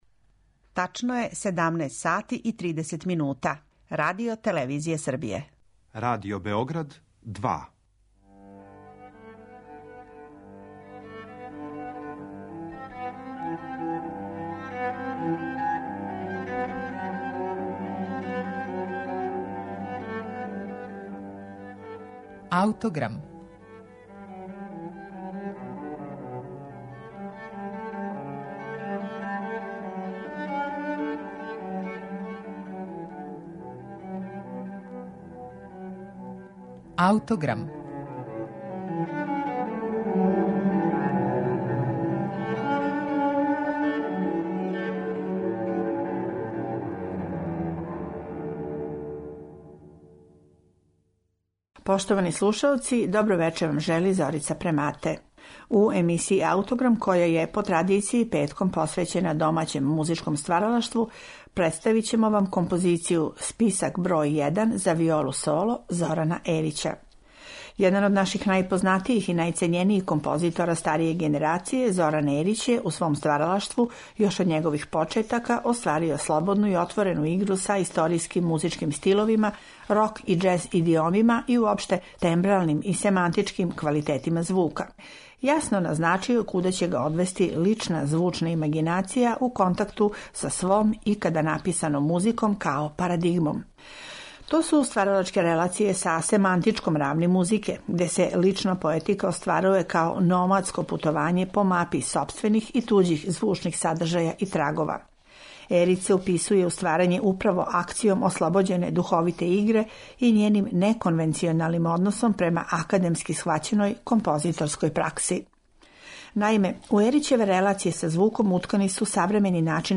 за виолу-солo